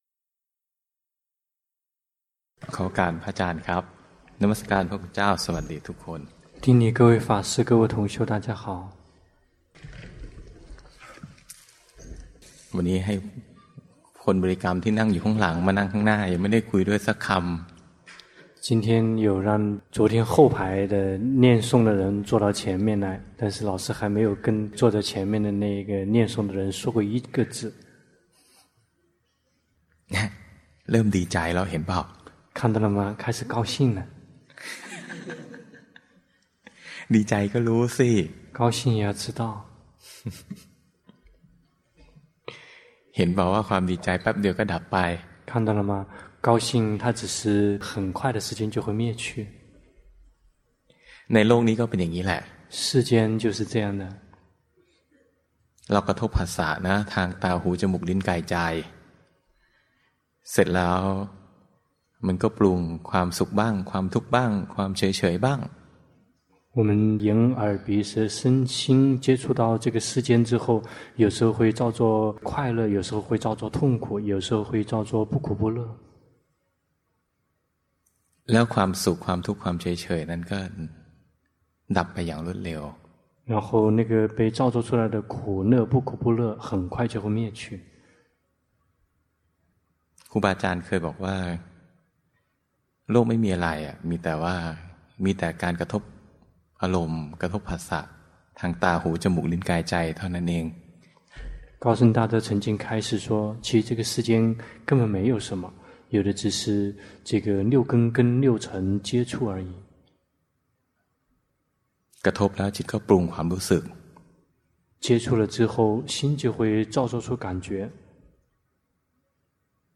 第十二屆泰國四念處禪修課程